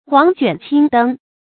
黃卷青燈 注音： ㄏㄨㄤˊ ㄐㄨㄢˋ ㄑㄧㄥ ㄉㄥ 讀音讀法： 意思解釋： 黃卷：古代書籍用黃低繕寫，因指書籍；青燈：油燈發青色的燈光，指油燈。